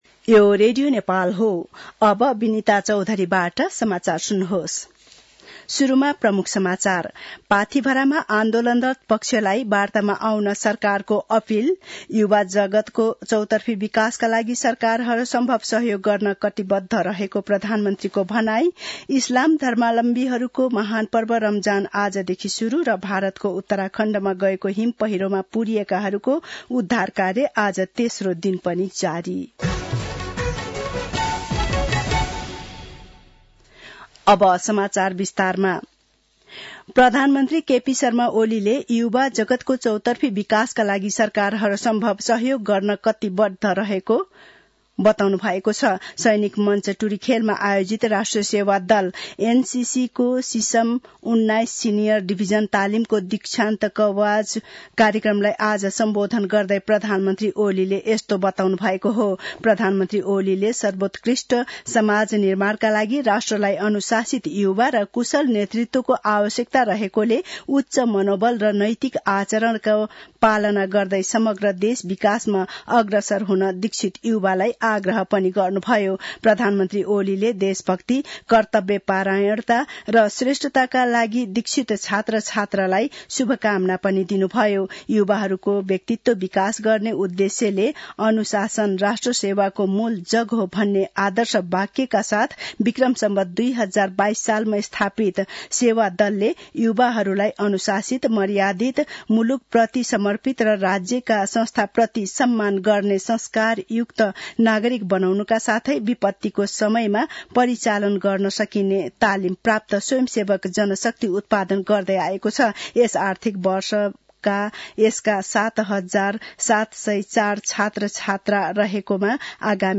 दिउँसो ३ बजेको नेपाली समाचार : १९ फागुन , २०८१
3-pm-news-.mp3